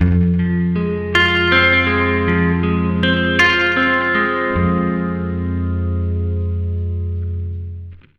80MINARP F-R.wav